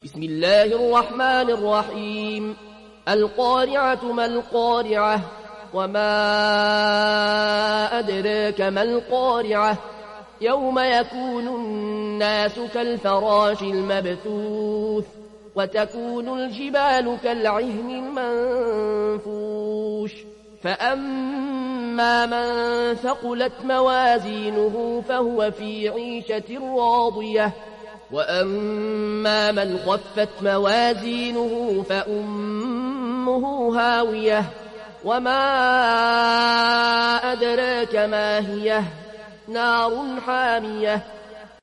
Riwayat Warsh an Nafi